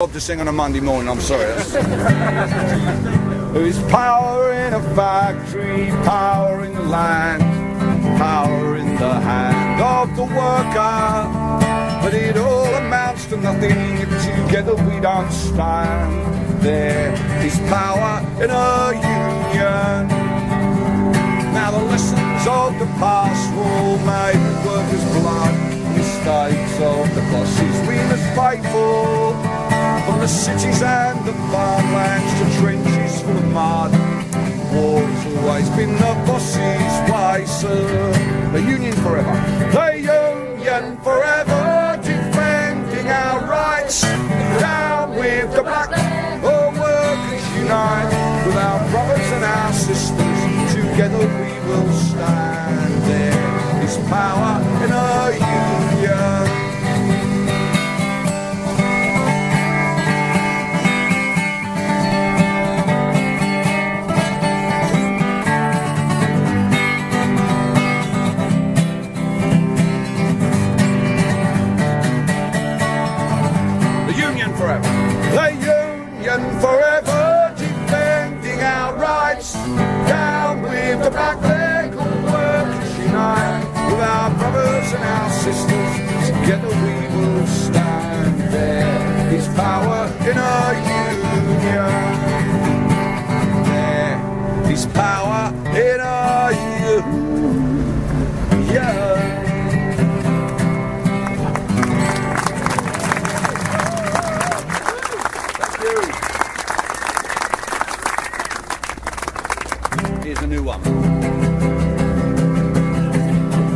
at Sheffield Occupy
English folk-punk singer